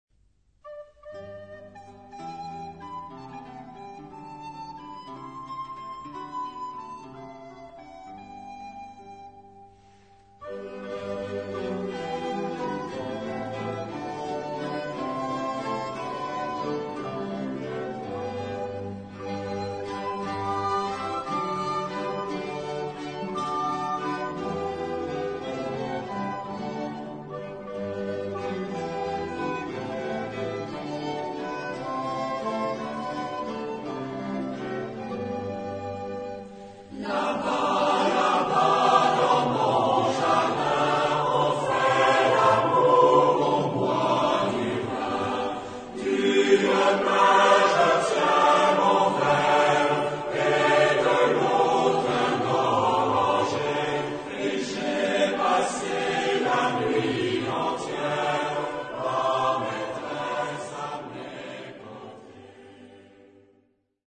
Genre-Stil-Form: Liedsatz ; weltlich ; Volkslied
Chorgattung: SATB  (4 gemischter Chor Stimmen )
Tonart(en): G-Dur